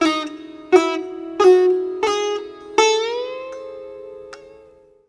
SITAR LINE25.wav